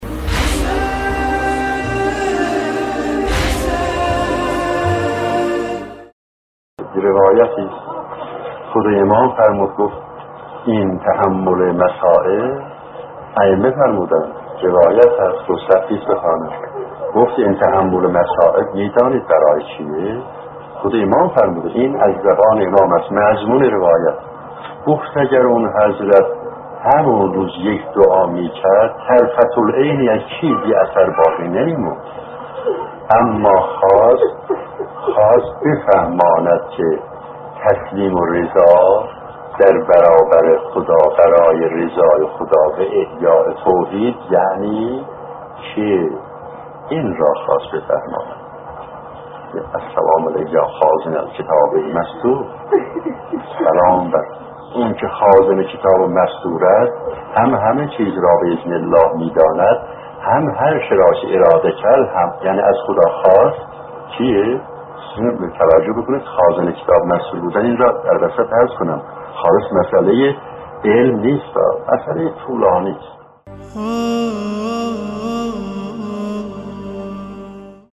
در ادامه، قسمت هفتم این سلسله‌گفتار را با عنوان «احیای تسلیم الرضا» می‌شنوید.